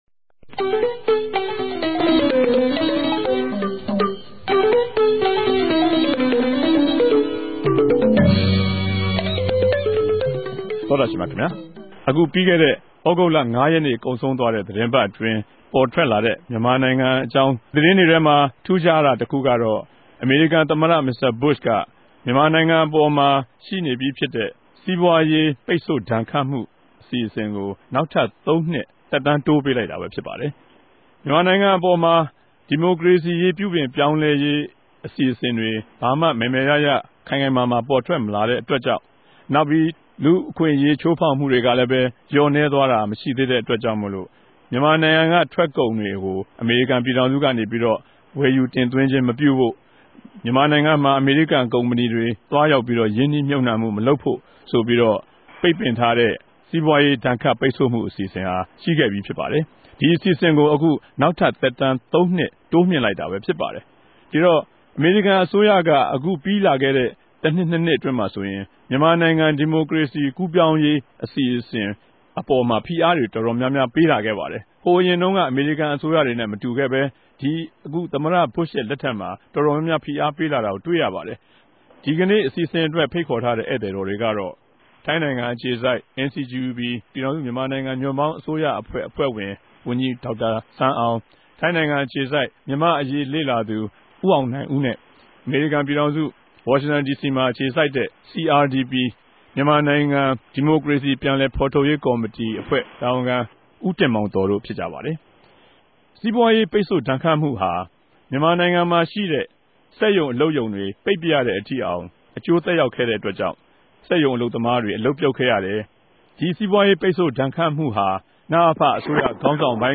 တပတ်အတြင်းသတင်းသုံးသပ်ခဵက် စကားဝိုင်း (၂၀၀၆ ုသဂုတ်လ ၅ရက်)